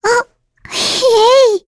Lavril-Vox_Happy4.wav